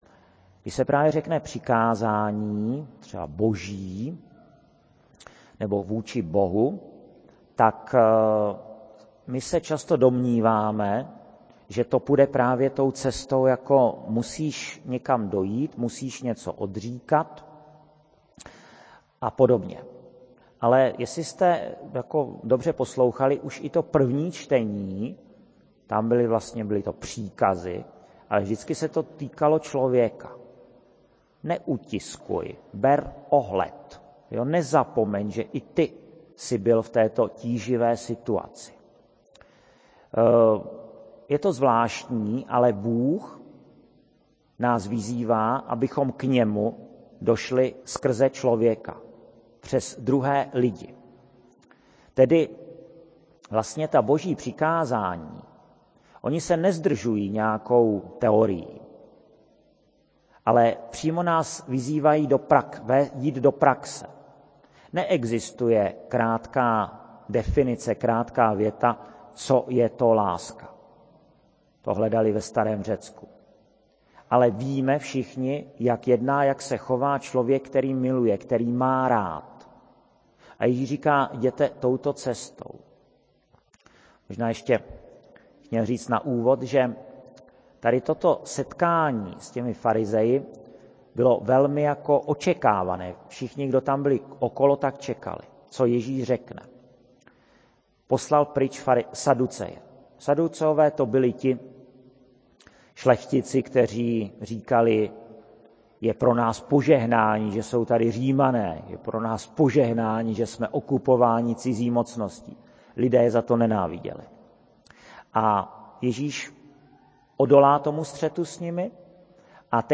Rubrika Homilie
26.10.2014, kostel sv. Jakuba ve Veverské Bítýšce
[MP3, mono, 16 kHz, VBR 21 kb/s, 1.07 MB]
homilie0731.mp3